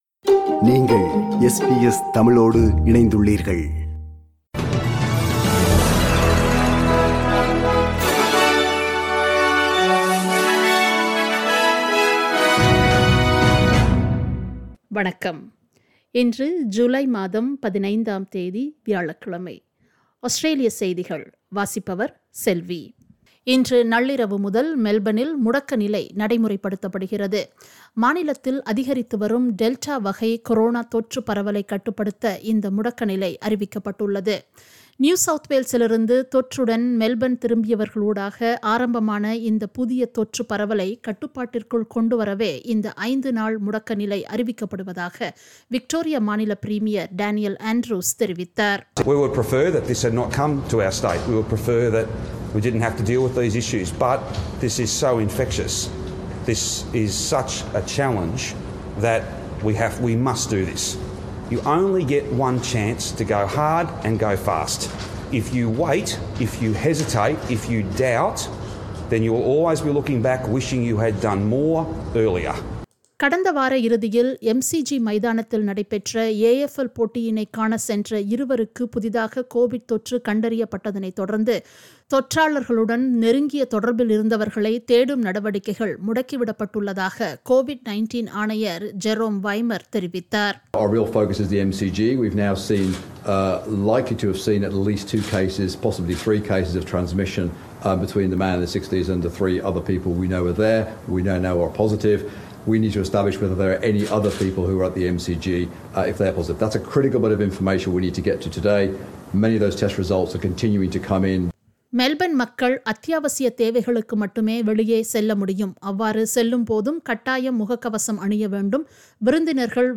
SBS தமிழ் ஒலிபரப்பின் இன்றைய (வியாழக்கிழமை 15/07/2021) ஆஸ்திரேலியா குறித்த செய்திகள்.